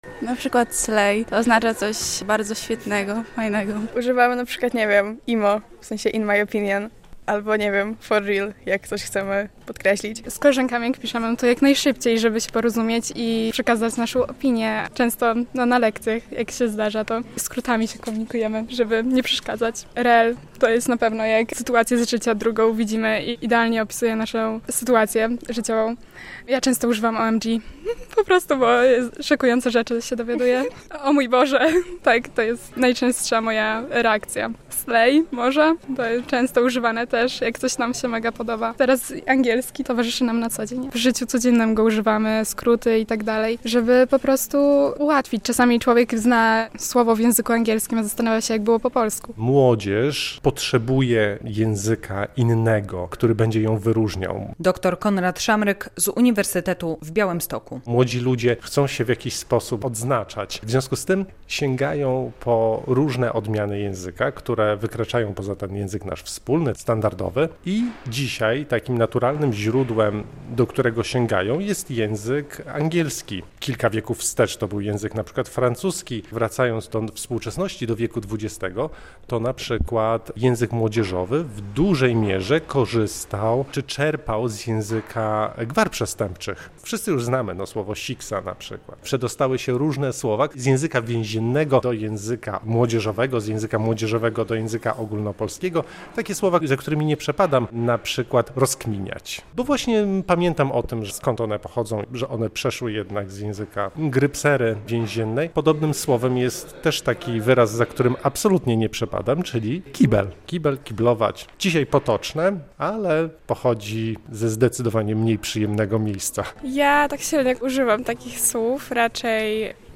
Dzień Języka Ojczystego - relacja
Pytana przez nas młodzież przyznaje, że zwrotów tego typu używają znacznie więcej.